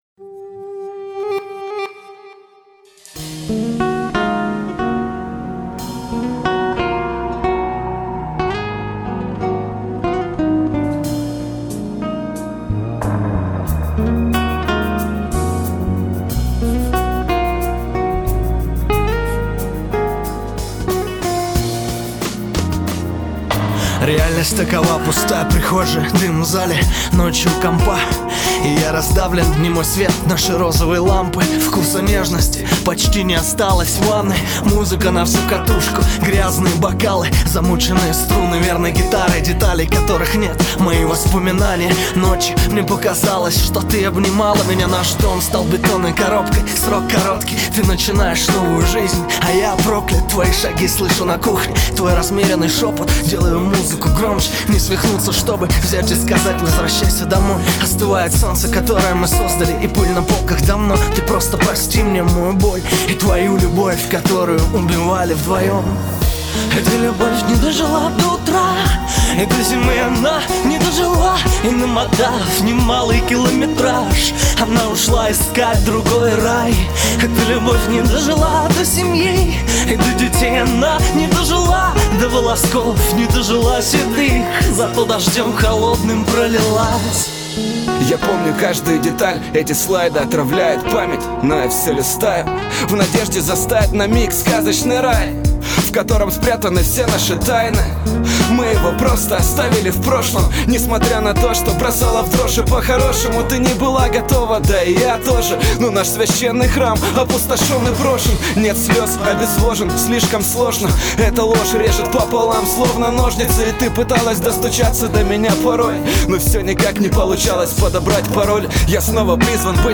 Главная » Каталог музыки » Русский рэп